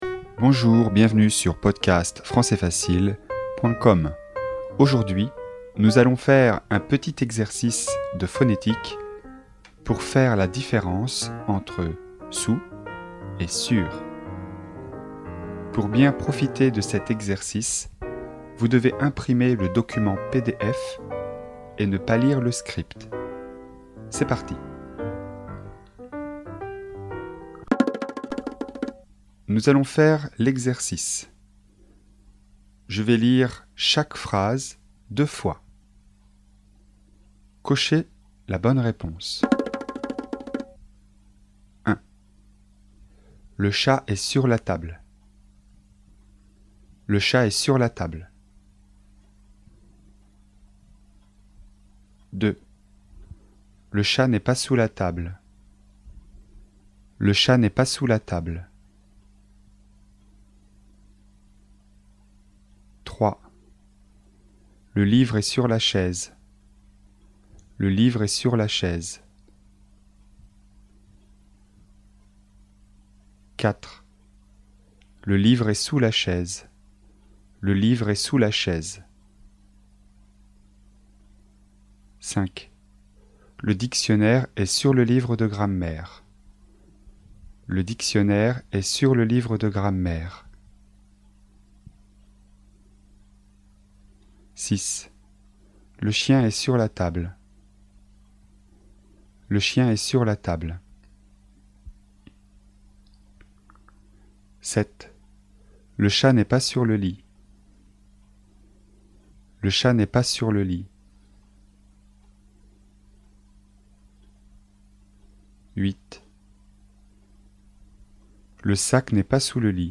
Exercice de phonétique et de prononciation, niveau débutant (A1) sur les sons « sur » et « sous »